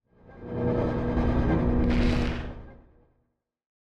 conjuration-magic-sign-rune-outro.ogg